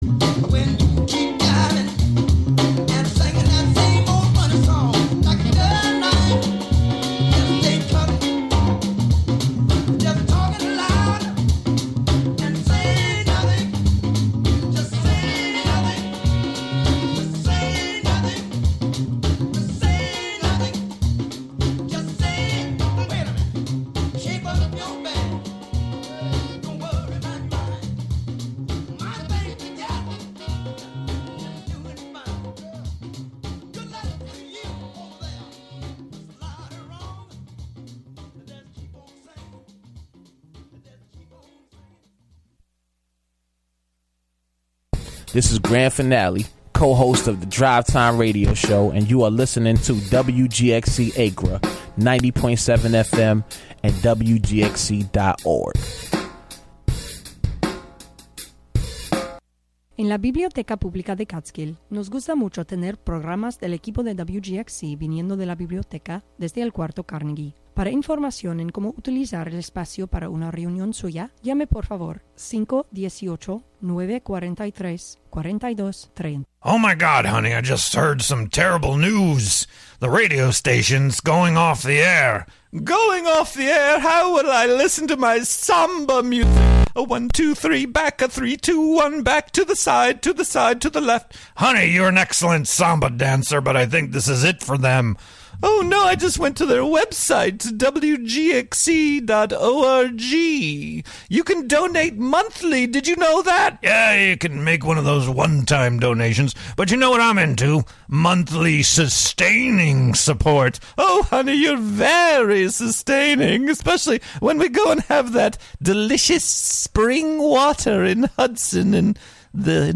Broadcast live from the Carnegie Room of the Catskill Library.